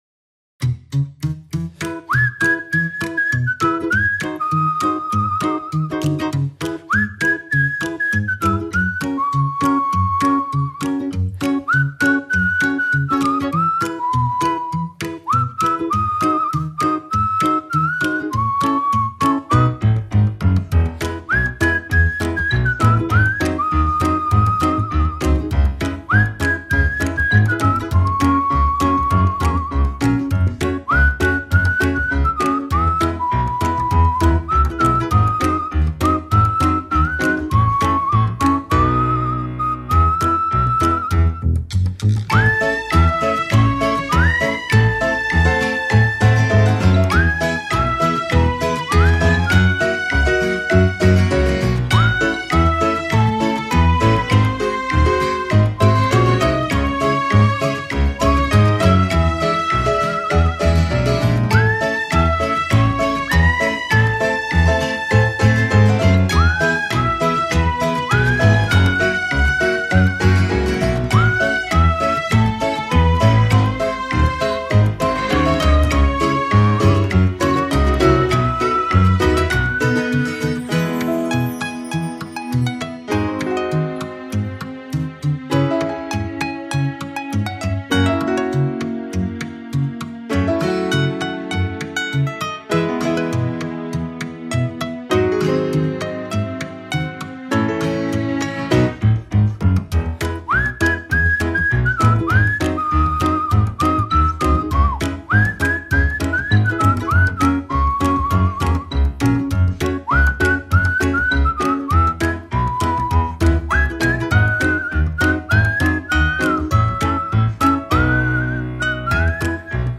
Веселая музыка для детских соревнований (фоновая)